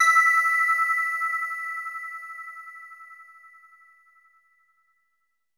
LEAD E5.wav